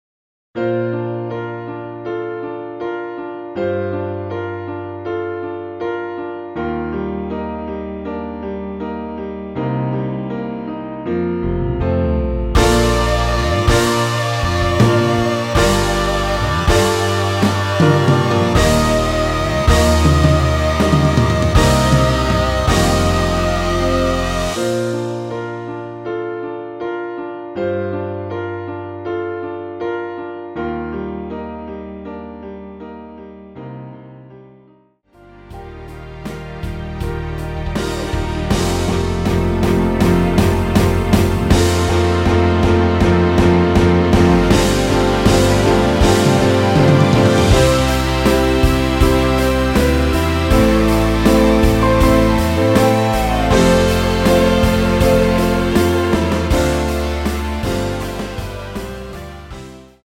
Bb
앞부분30초, 뒷부분30초씩 편집해서 올려 드리고 있습니다.
중간에 음이 끈어지고 다시 나오는 이유는
곡명 옆 (-1)은 반음 내림, (+1)은 반음 올림 입니다.